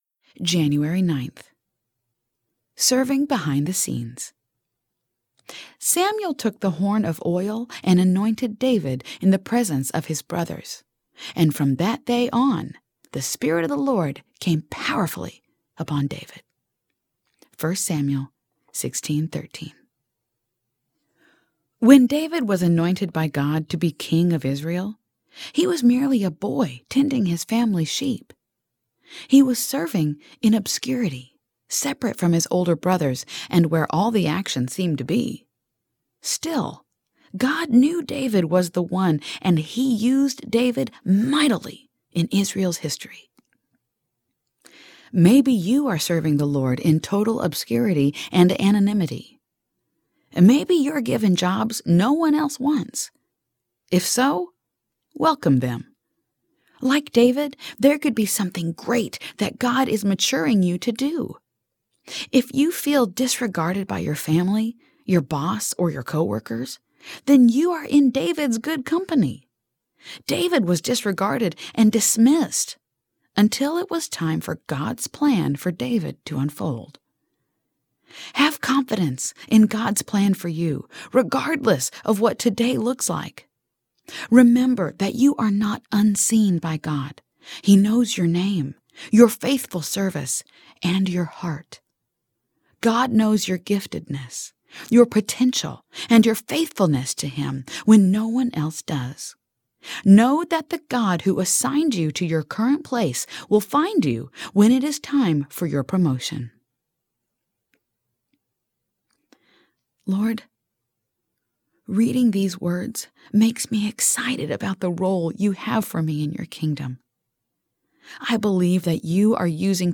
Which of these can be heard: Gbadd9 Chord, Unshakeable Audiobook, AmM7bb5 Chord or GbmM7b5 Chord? Unshakeable Audiobook